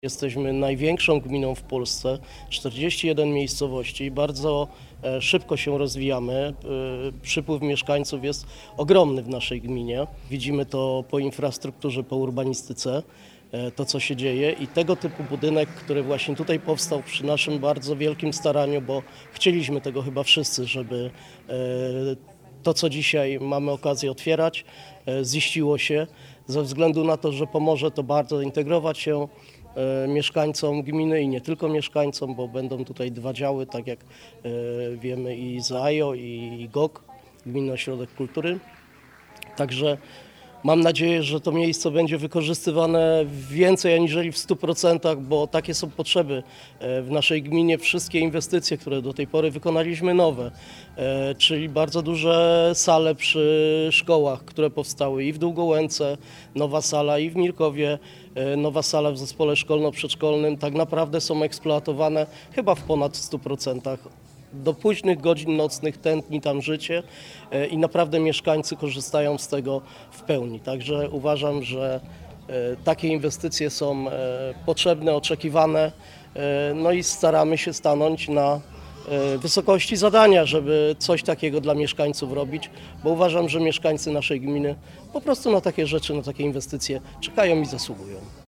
Dariusz Podyma, przewodniczący komisji oświaty w Radzie Gminy podkreśla, że otwarcie Gminnego Ośrodka Kultury jest bardzo ważne dla całej gminy Długołęka.